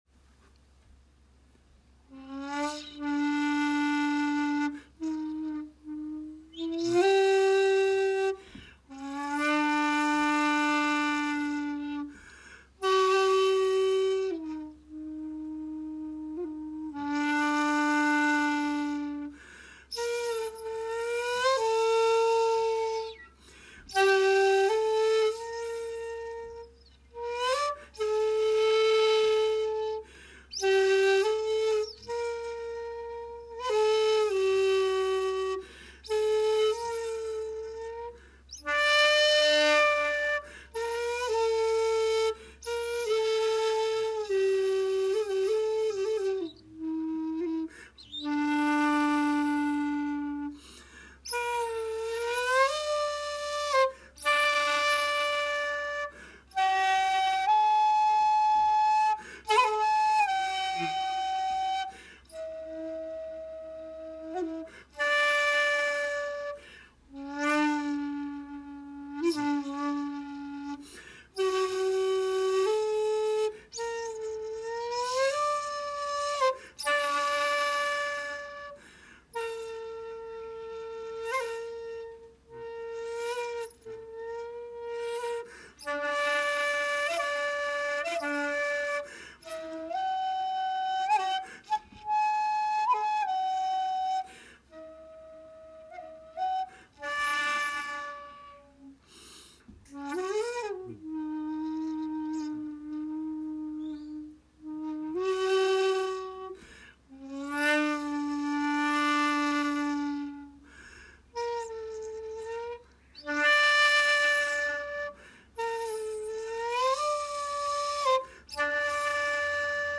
今日の打ち止めに尺八を本堂前で吹きました。
（尺八音源「手向」浄瑠璃寺にて）